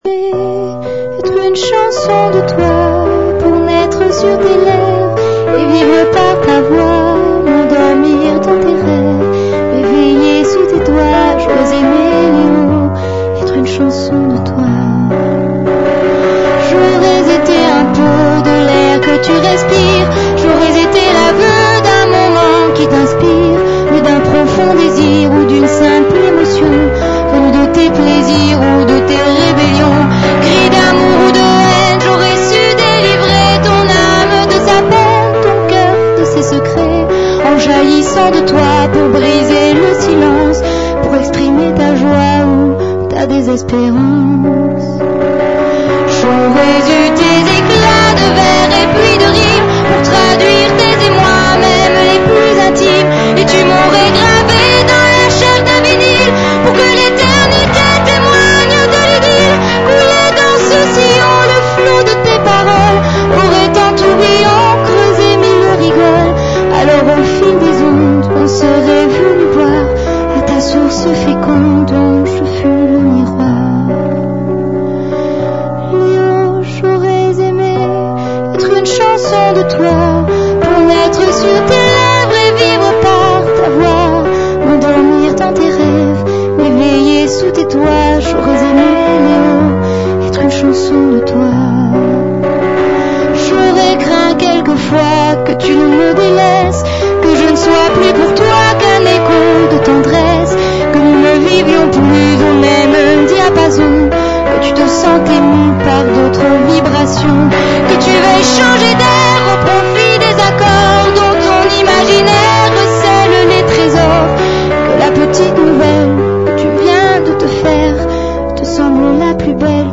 (Attention: enregistrement non pro fait par un fan)